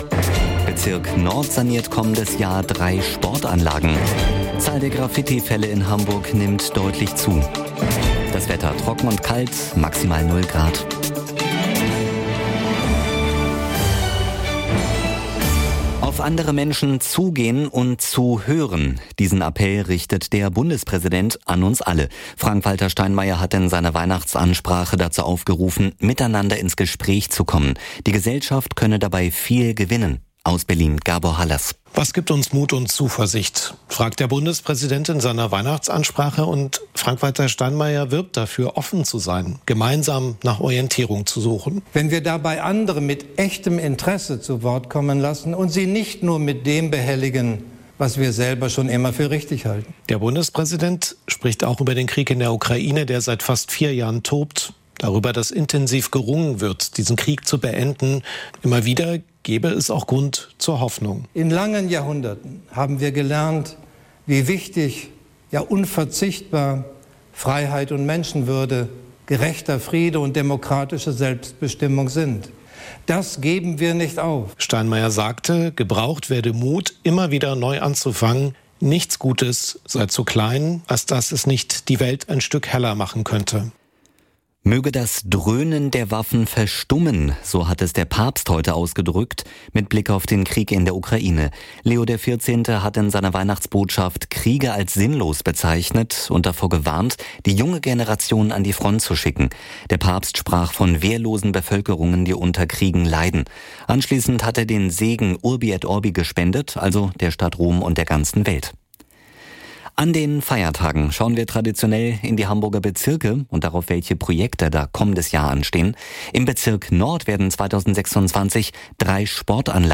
Aktuelle Ereignisse, umfassende Informationen: Im Nachrichten-Podcast von NDR 90,3 hören Sie das Neueste aus Hamburg und der Welt.